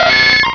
pokeemerald / sound / direct_sound_samples / cries / nidorino.aif